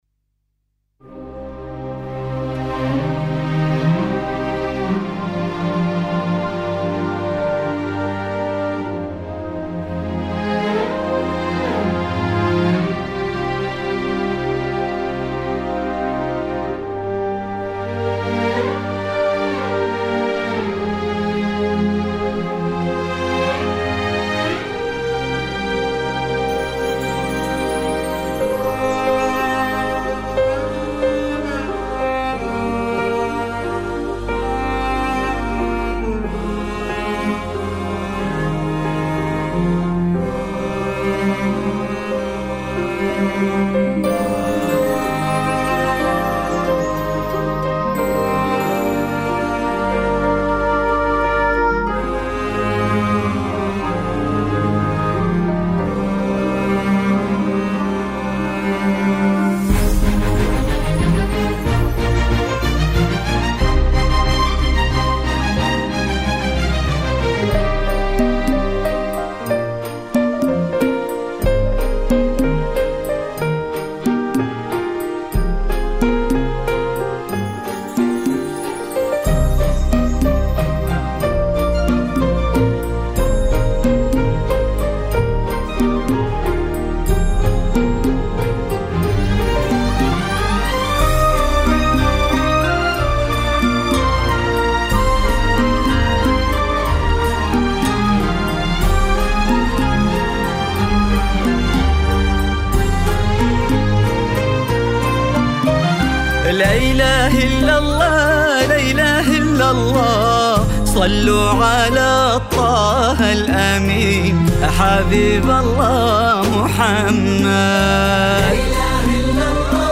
زفة عروس